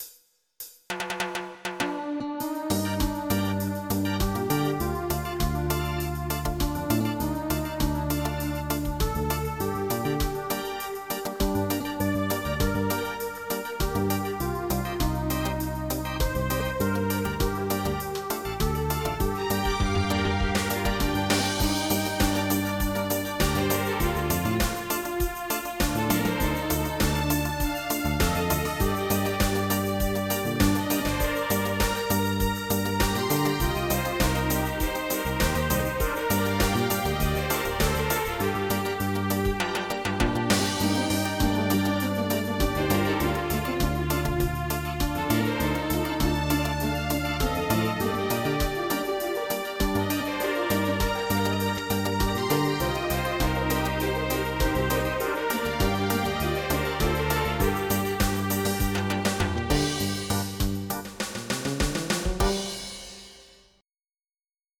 MIDI Music File
reggae_birthday_death_march.mp3